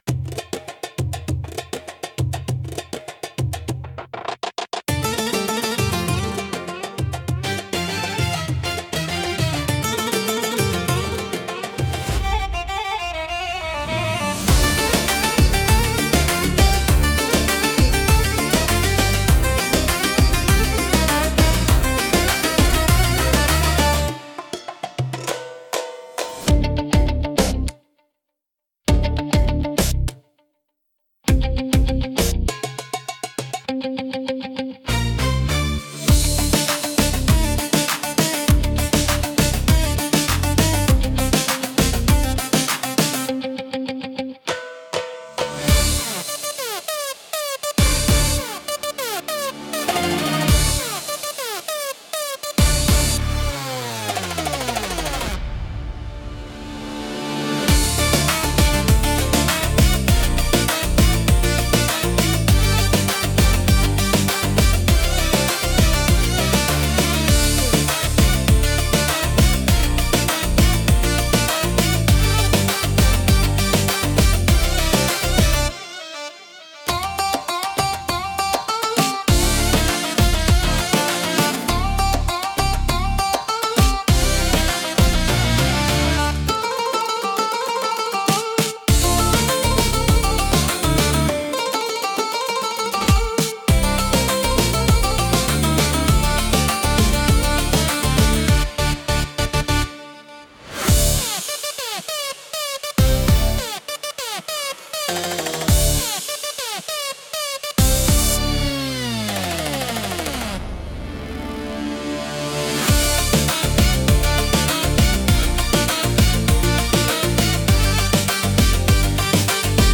ウードやカヌーン、ダラブッカなどの民族楽器が使われ、神秘的でエキゾチックな雰囲気を醸し出します。
独特のメロディとリズムで聴く人の感覚を刺激します。